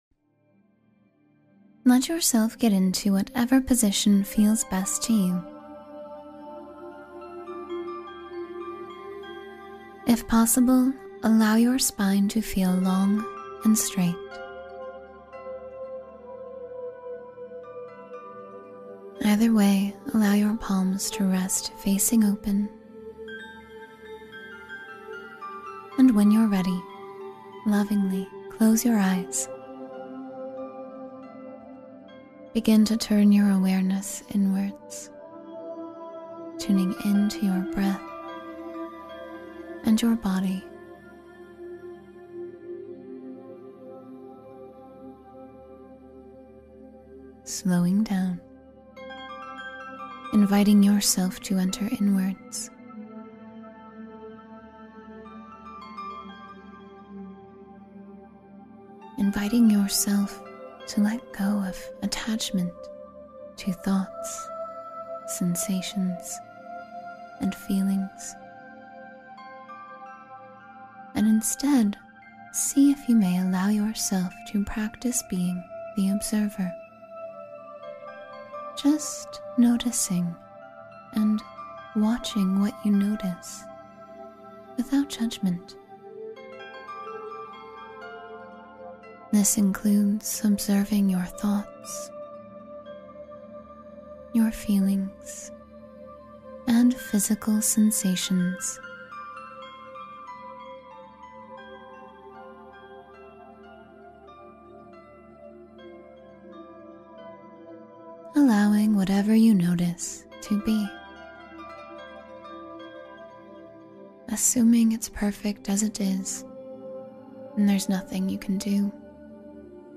Surround Yourself with Healing White Light — Meditation for Protection and Healing